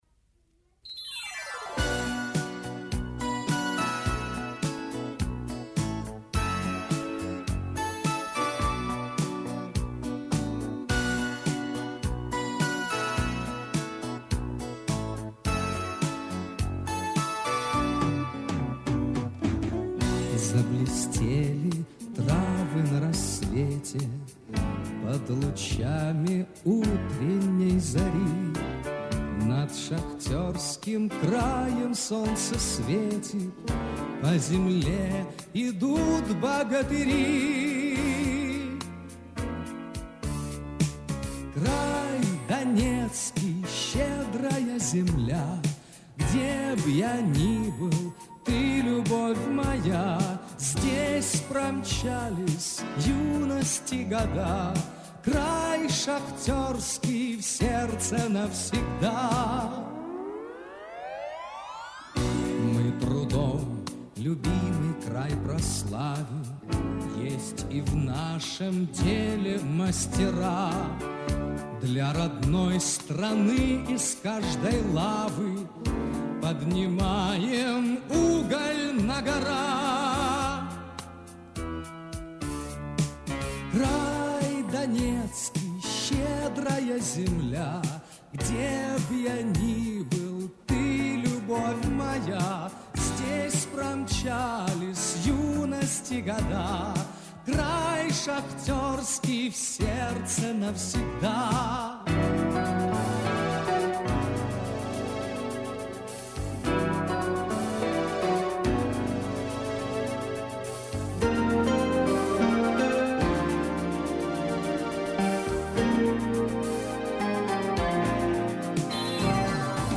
Солисты: